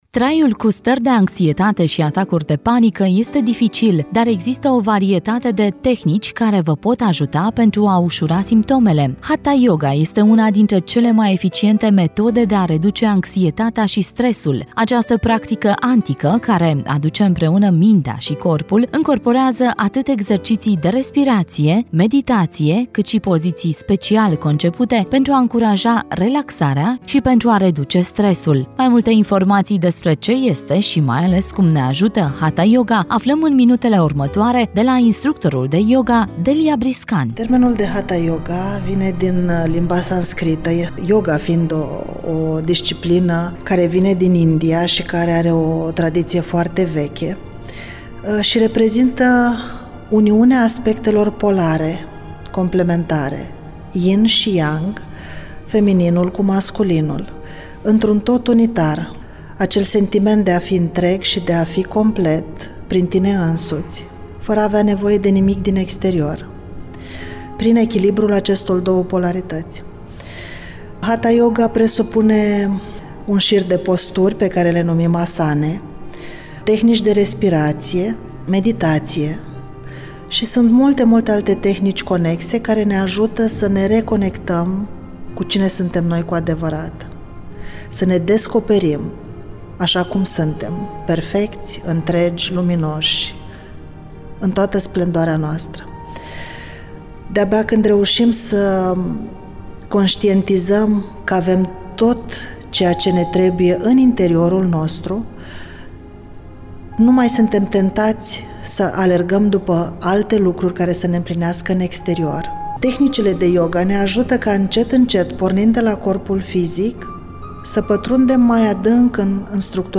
Mai multe informaţii despre ce este şi, mai ales, cum ne ajută Hatha Yoga, aflăm în minutele urmatoare de la instructorul de Yoga